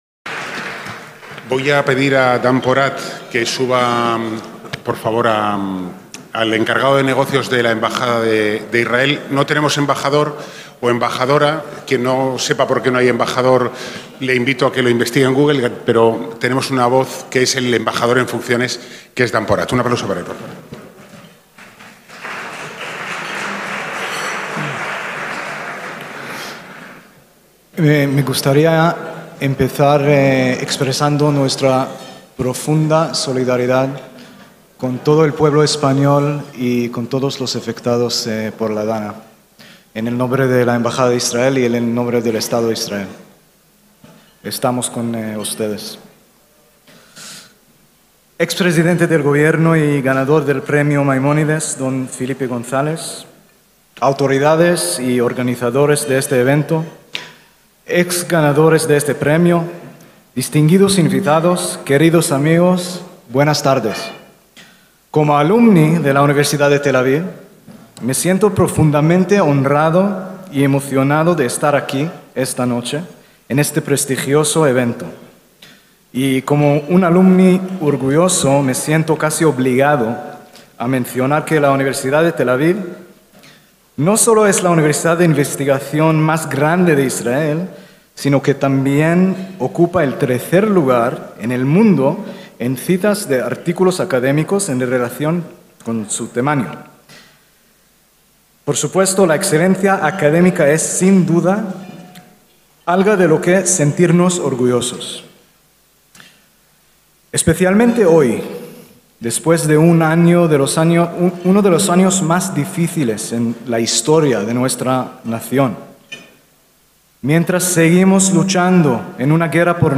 ACTOS EN DIRECTO - El pasado 13 de noviembre de 2024 se celebró en el Real Casino de Madrid el acto de entrega del Premio Maimónides 2024, en su 7ª edición, al ex Presidente de Gobierno de España, Felipe González Márquez.